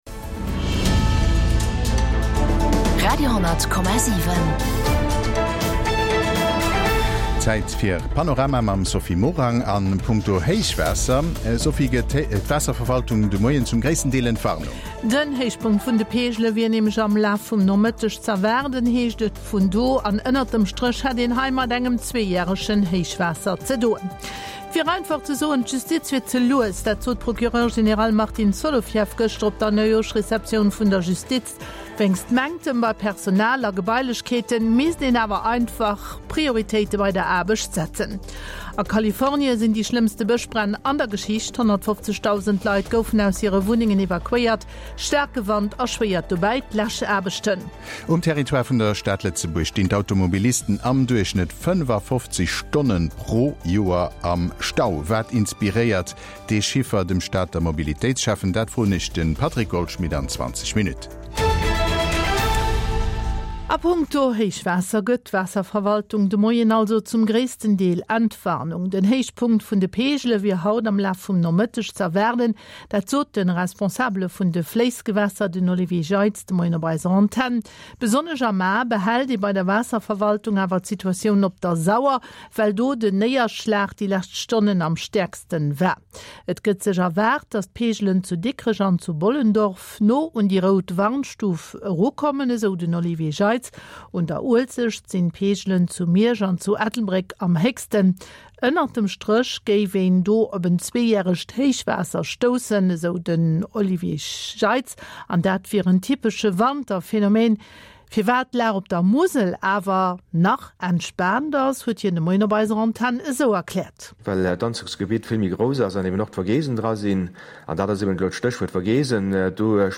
National an international Noriichten